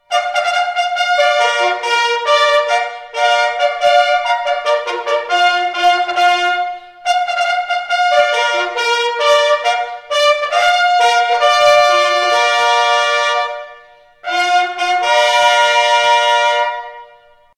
HM-Royal-Marines-Bugle-fanfare.mp3